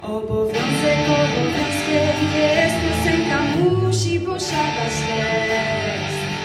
wystąpił w piątkowy (17.04) wieczór w Kawiarence Artystycznej w Suwałkach. Grupę tworzą uzdolnieni muzycznie nastolatkowie, którzy wykonali szereg popularnych hitów muzycznych.